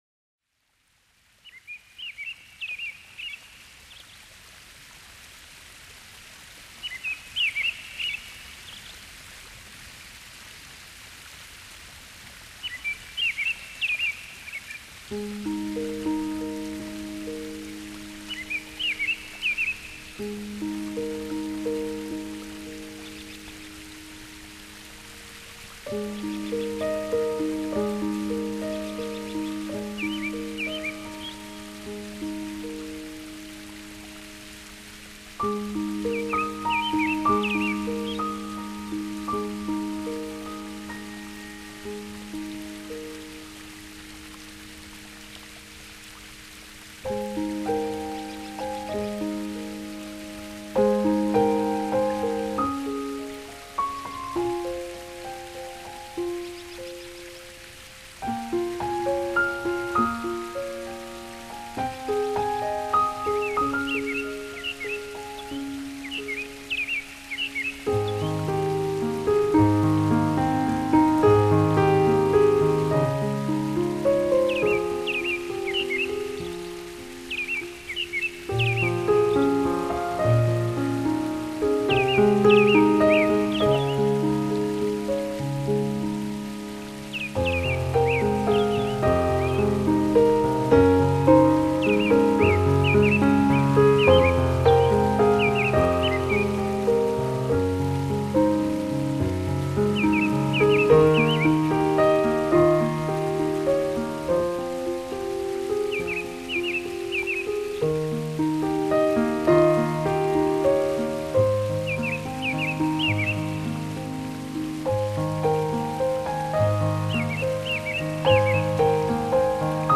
ça fait cache misère (les bruits d'eau)